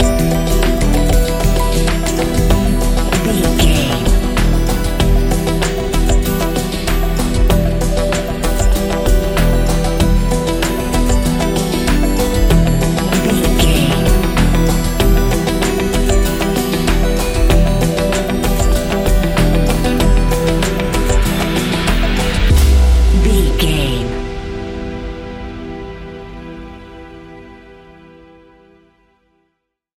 Ionian/Major
E♭
techno
trance
synths
synthwave